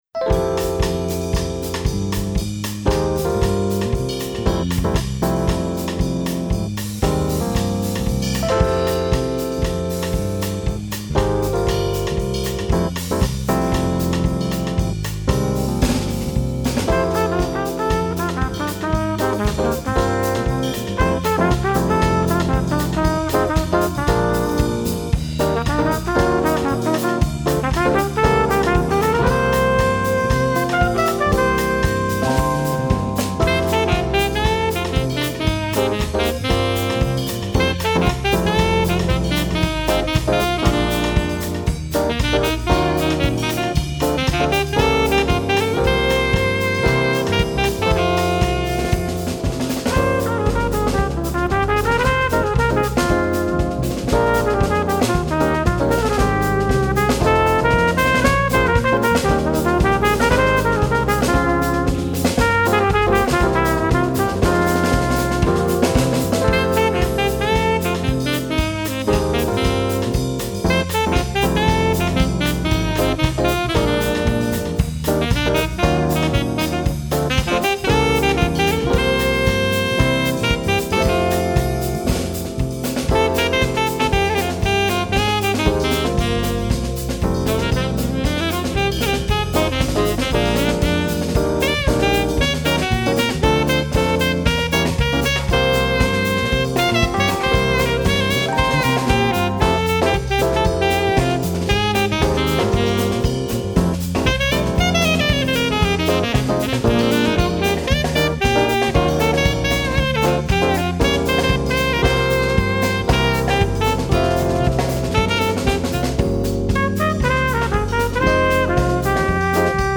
Combo  (4 Minuten)